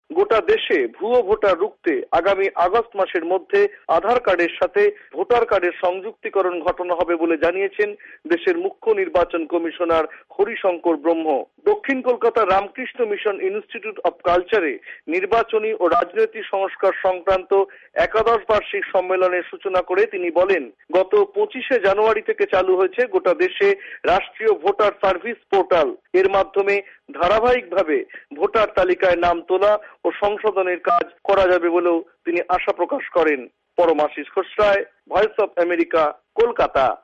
ভয়েস অফ এ্যামেরিকার কলকাতা সংবাদদাতাদের রিপোর্ট